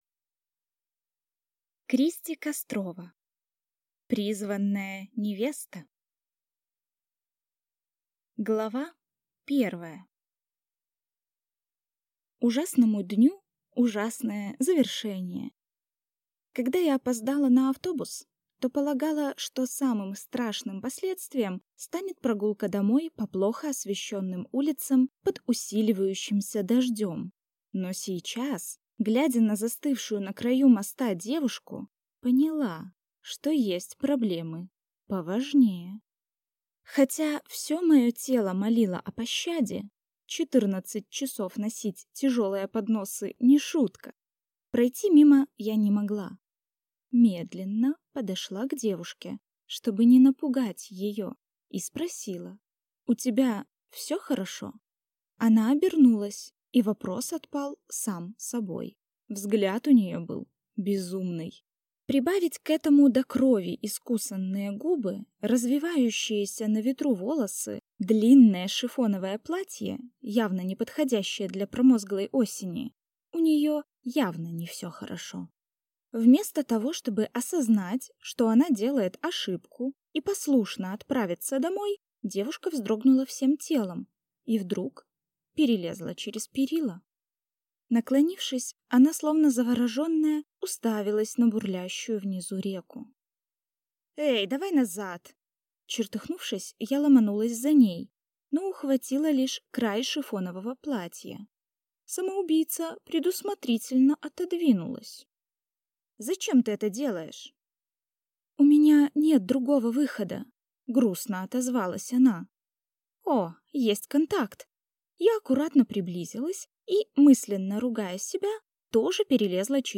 Аудиокнига Призванная невеста | Библиотека аудиокниг